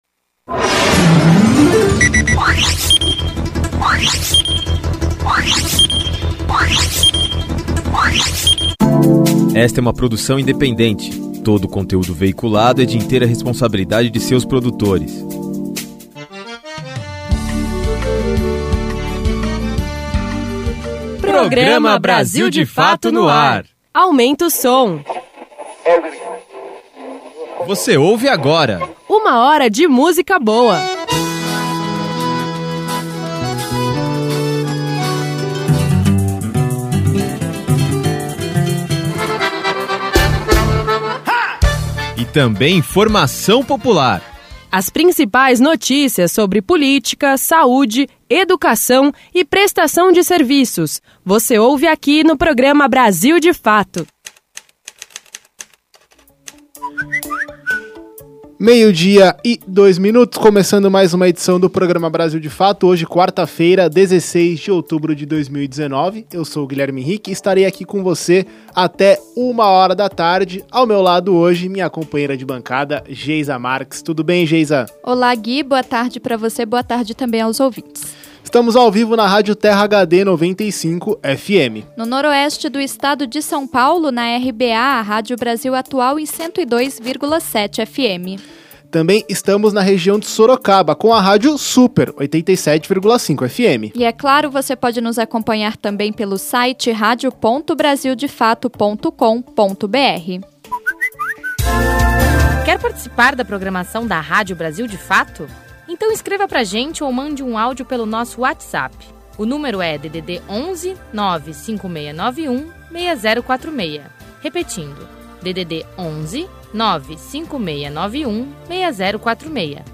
No dia 16 de outubro, comemora-se o Dia Mundial da Alimentação e o Programa Brasil de Fato desta quarta-feira (16) traz reportagens associadas ao tema.